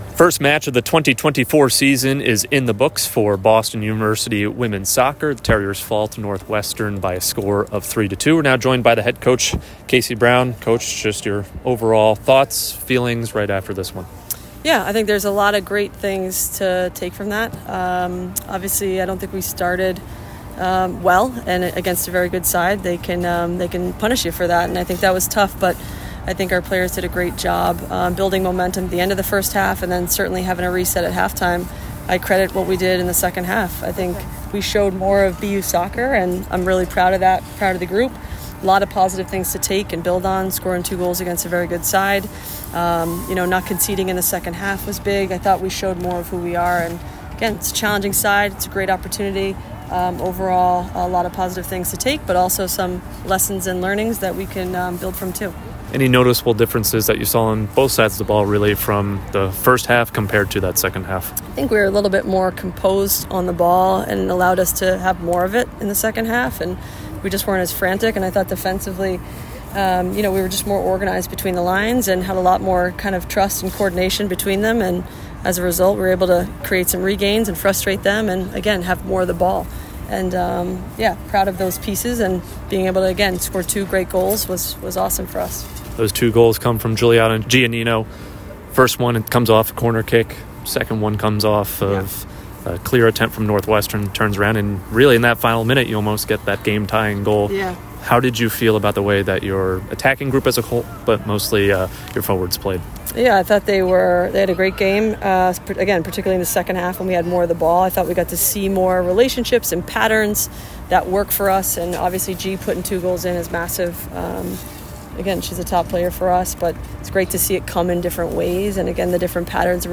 Women's Soccer / Northwestern Postgame Interview (8-15-24) - Boston University Athletics